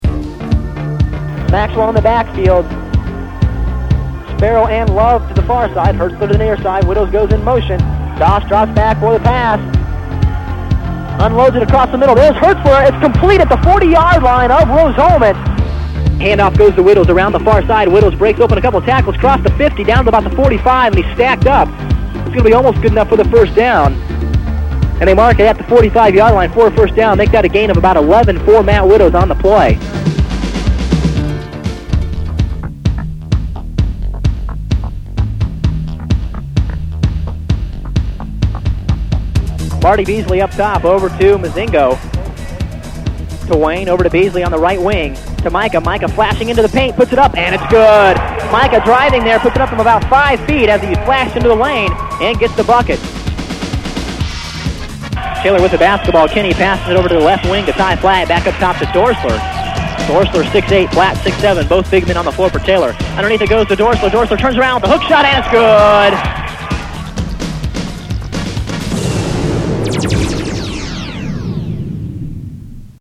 I hosted a radio show on this Milwaukee station.